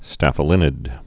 (stăfə-lĭnĭd, -līnĭd)